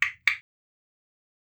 claquement-6.wav